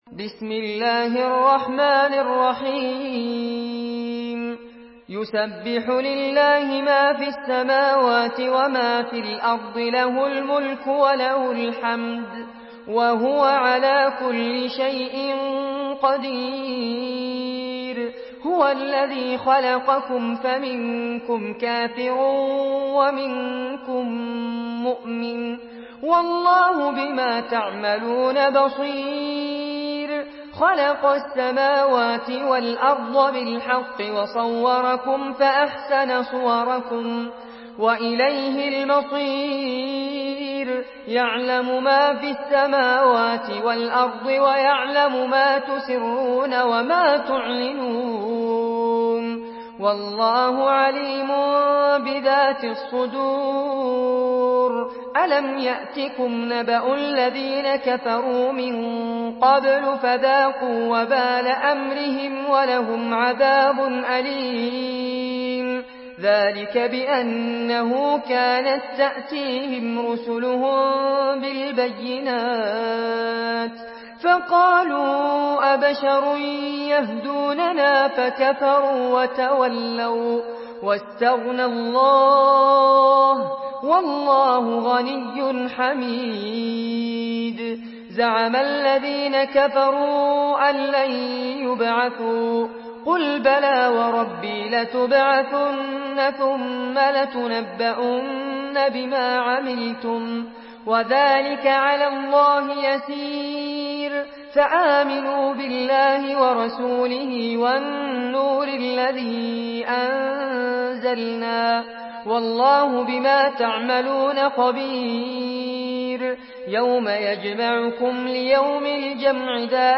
سورة التغابن MP3 بصوت فارس عباد برواية حفص
مرتل